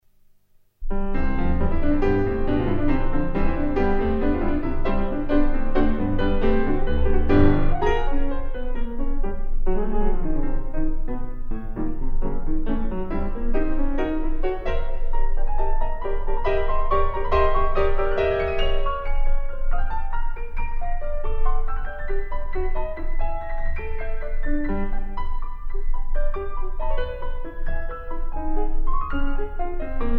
PIANO from Alfred Newman Hall